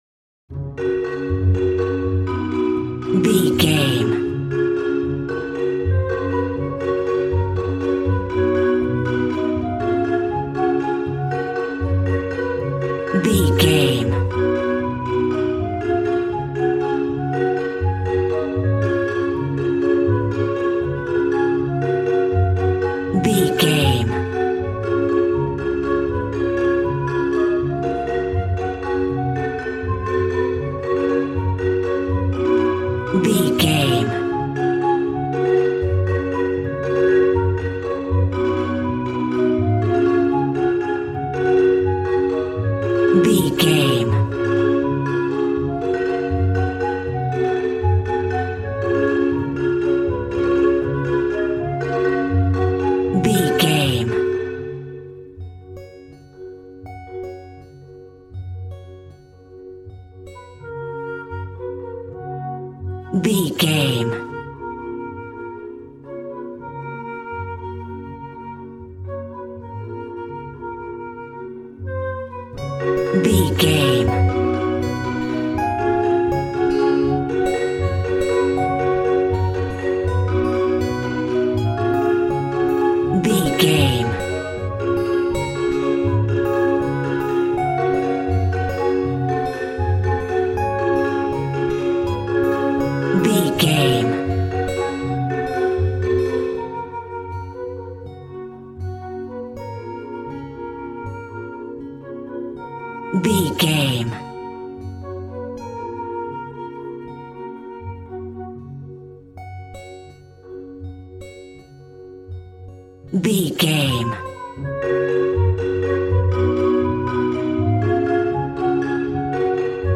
Ionian/Major
G♭
positive
cheerful/happy
joyful
drums
acoustic guitar